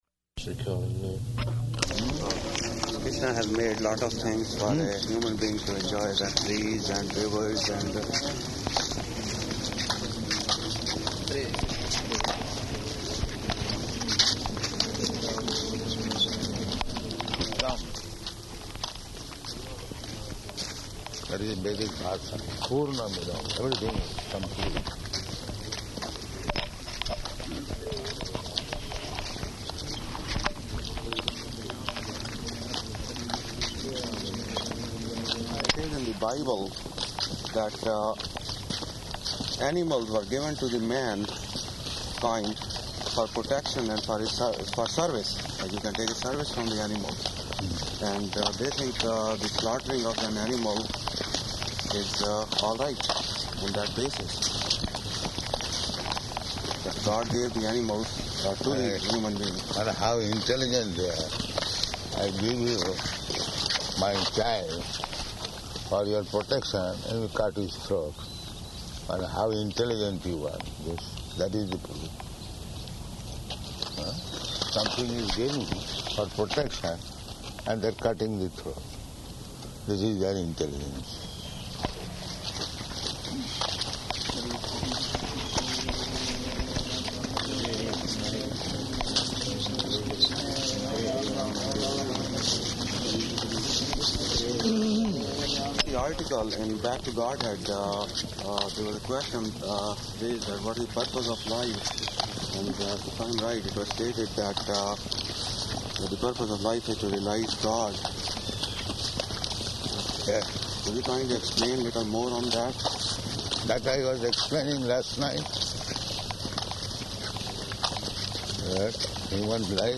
-- Type: Walk Dated: June 21st 1976 Location: Toronto Audio file